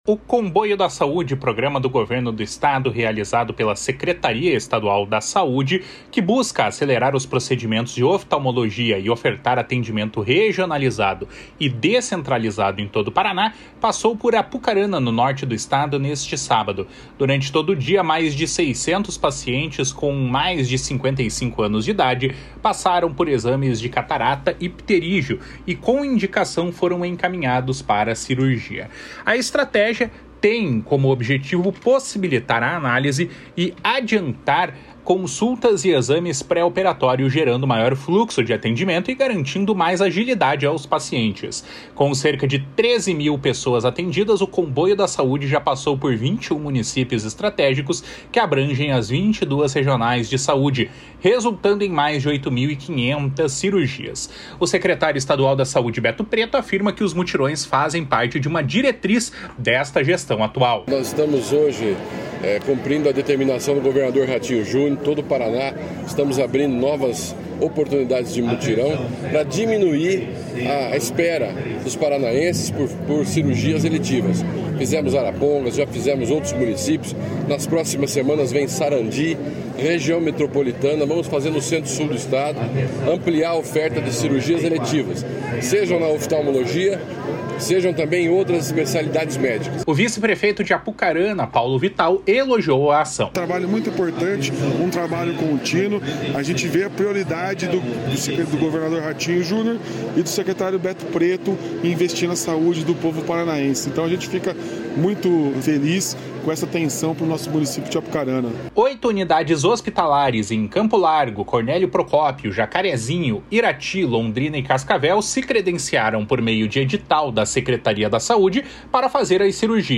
O secretário estadual da Saúde, Beto Preto, afirma que os mutirões fazem parte de uma diretriz desta gestão atual.
O vice-prefeito de Apucarana, Paulo Vital, elogiou a ação.